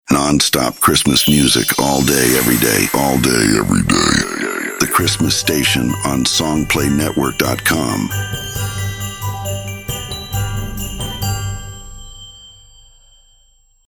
The-Christmas-Station-id-1.mp3